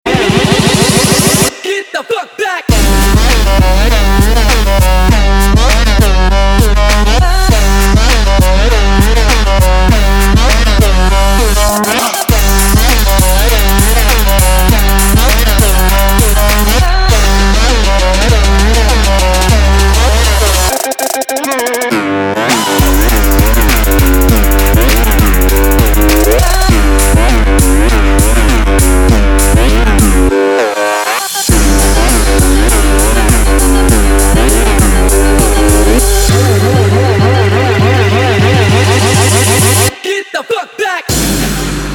Trap
клубнячок